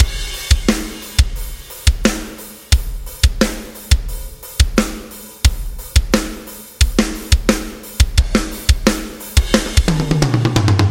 Metal Mayhem4 HEAVY DRUMS2 176
Tag: 176 bpm Heavy Metal Loops Drum Loops 1.84 MB wav Key : Unknown Reason